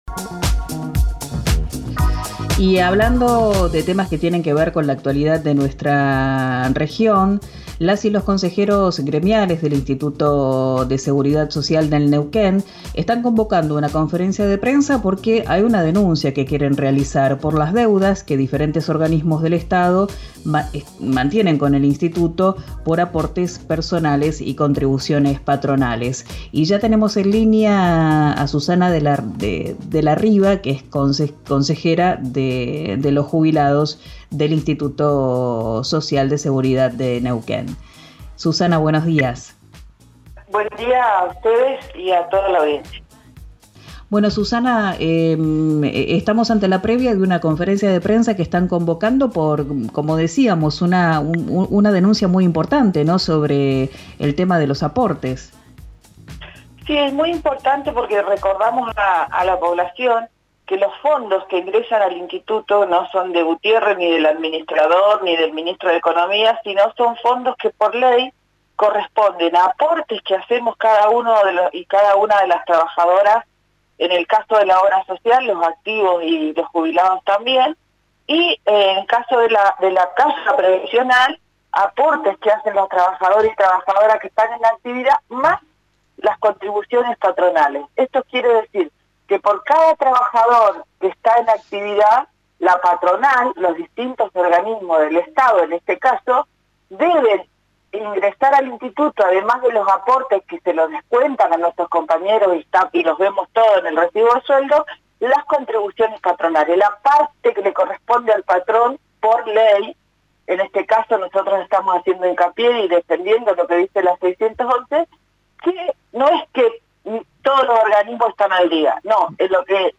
en diálogo con el programa “Arranquemos” de RIO NEGRO RADIO